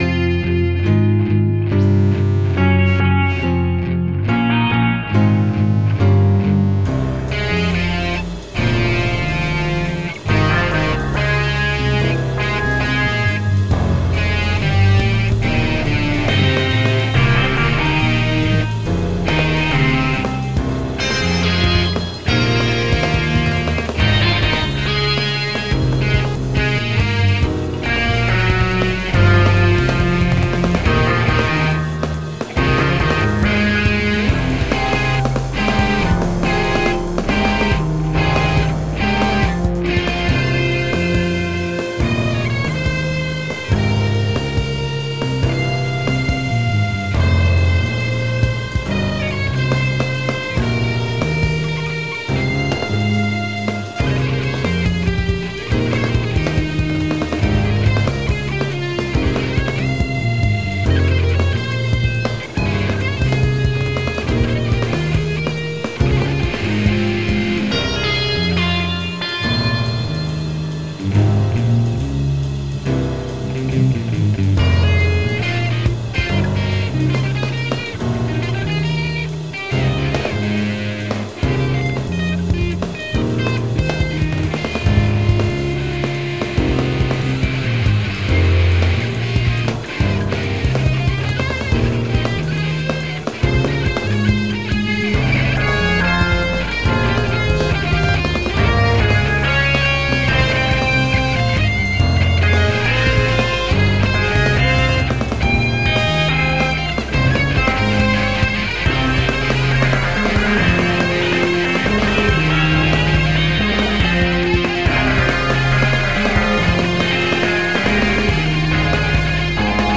Electronic, Jazz (2023)